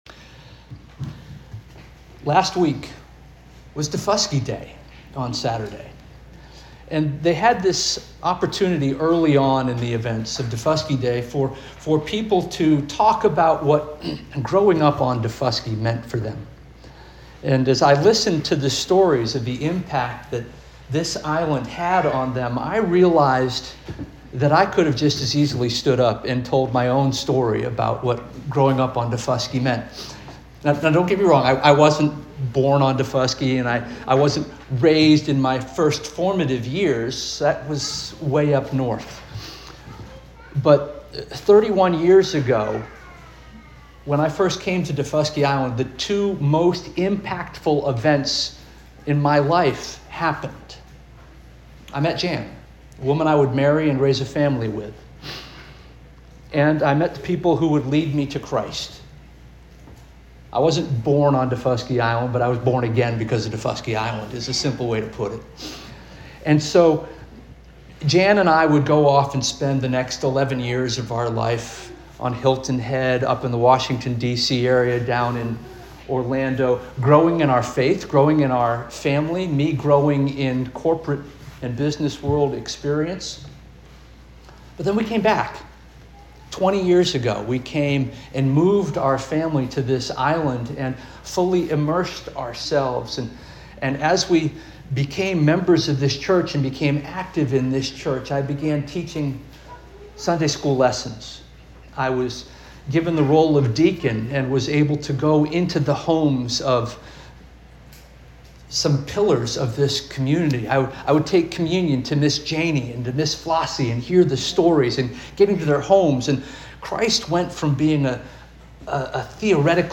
July 6 2025 Sermon